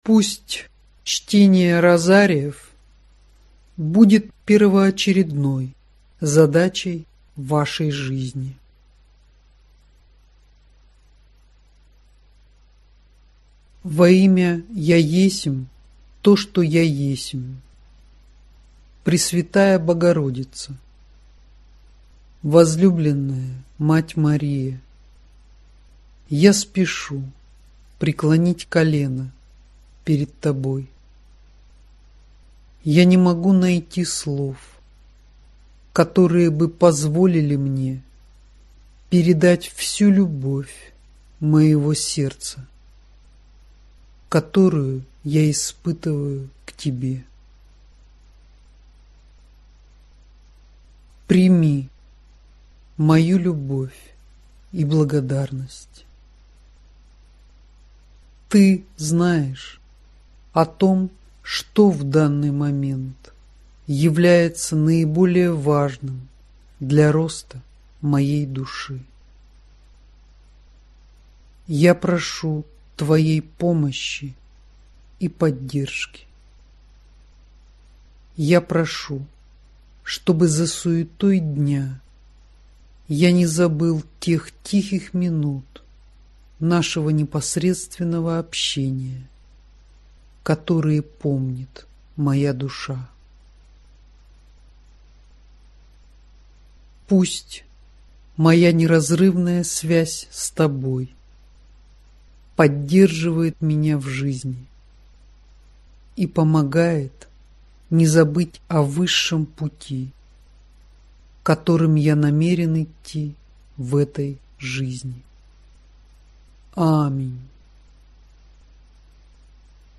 Аудиокнига Краткие Розарии Матери Марии № 6, № 7 | Библиотека аудиокниг